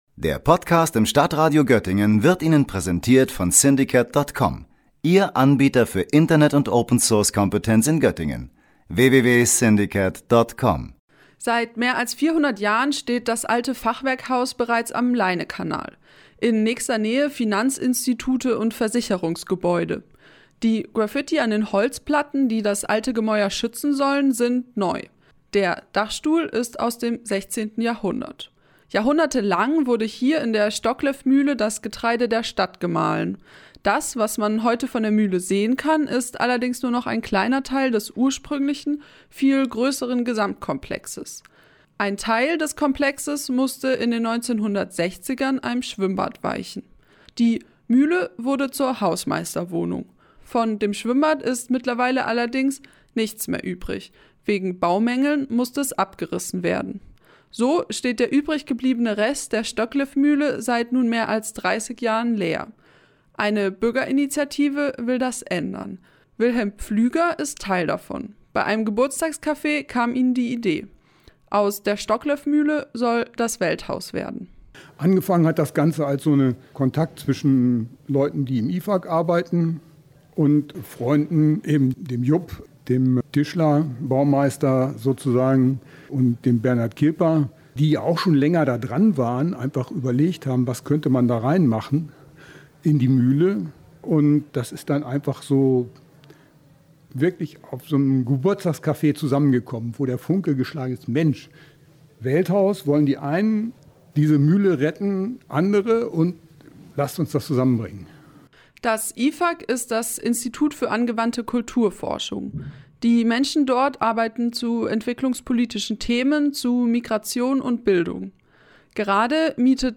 Die Stockleff-Mühle am Leinekanal soll renoviert werden. Ein Bürger*innenbündnis hat seine Pläne für die alte Mühle im Jungen Theater in Göttingen an der Bürgerstraße vorgestellt.